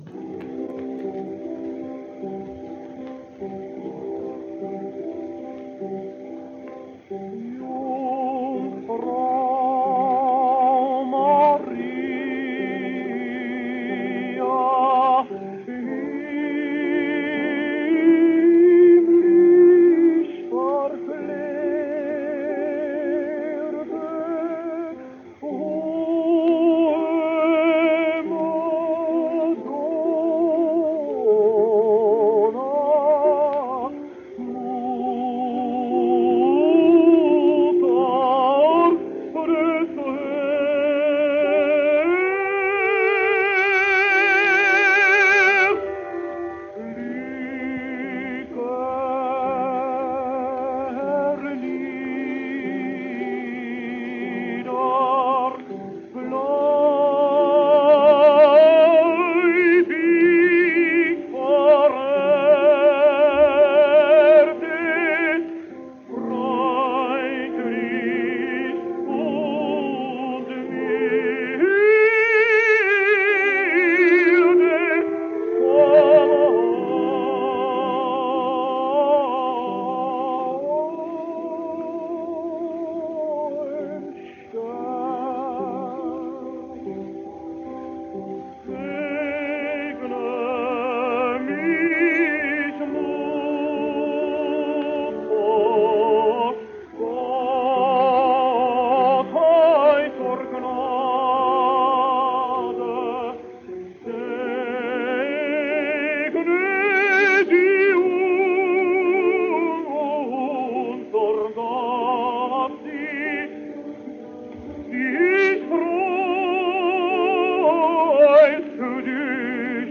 Here he is in an aria from a rare opera by Flotow, same man who wrote Ma Paris.